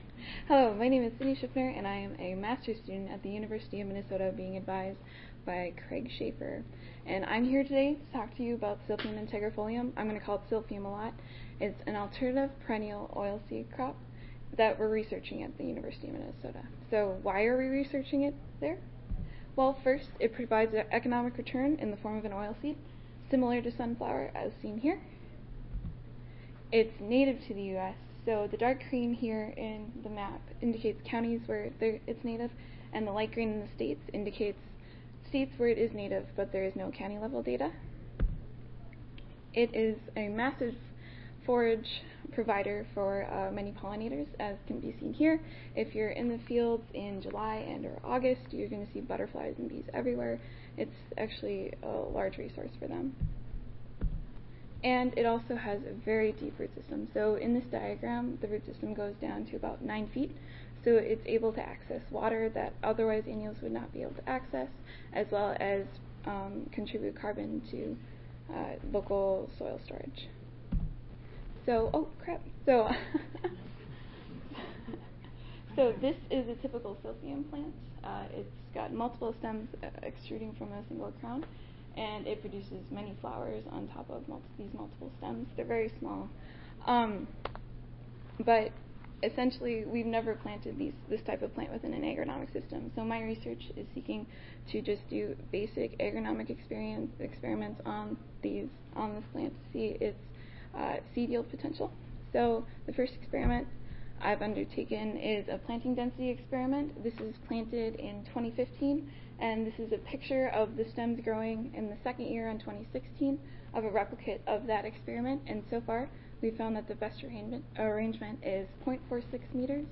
2017 Annual Meeting | Oct. 22-25 | Tampa, FL
This is a general Perennial Grain Development oral session comprised of five minute rapid contributed presentations.
Audio File Recorded Presentation